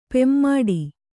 ♪ pemmaḍi